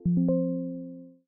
На этой странице собраны звуки Windows 11 — современные системные аудиоэффекты из новой версии ОС.